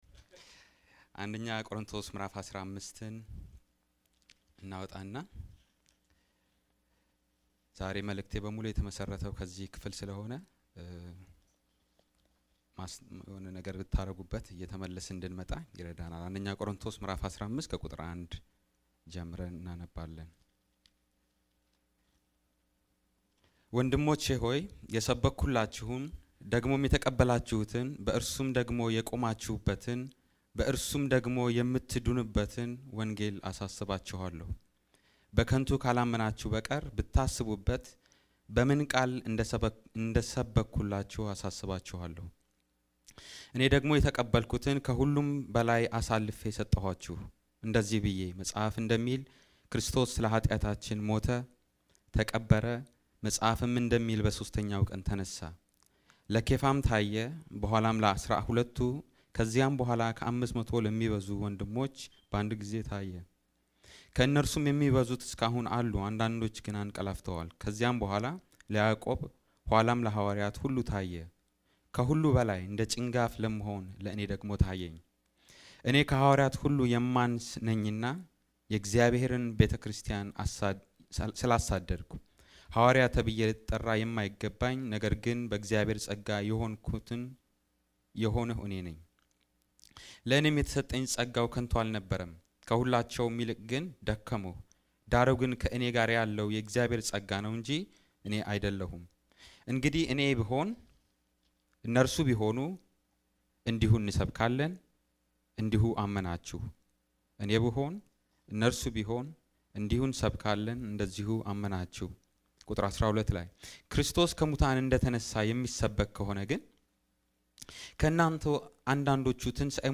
A sermon preached on December 19, 2010